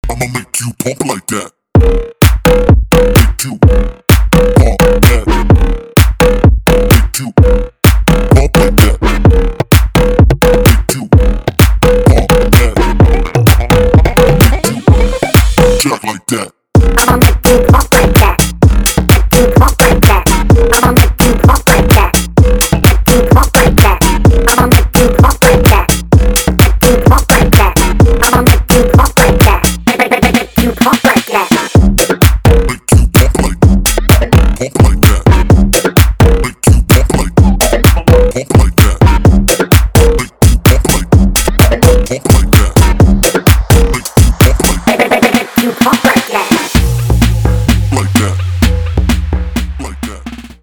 • Качество: 320, Stereo
мужской голос
женский голос
Electronic
Bass House
качающие
забавный голос